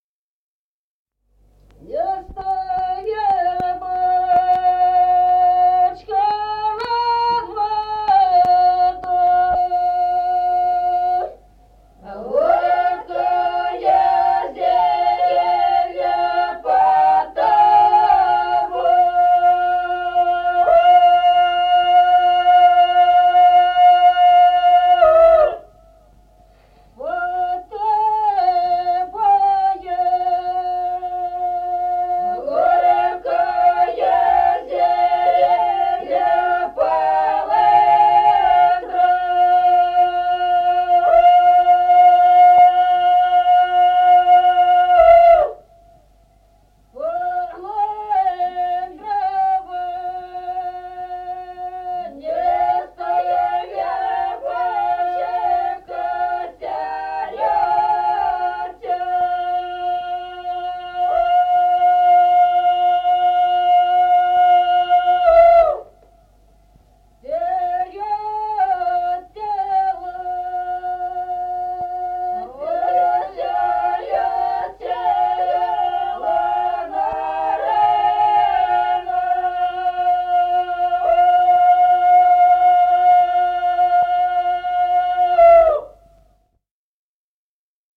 Песни села Остроглядово. Не стой, вербочка.